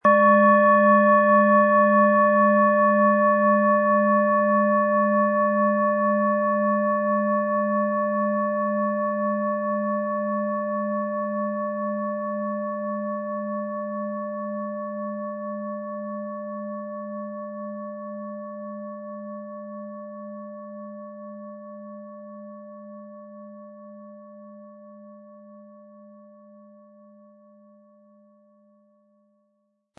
Von Meisterhand hergestellte Planetenton-Klangschale Uranus.
MaterialBronze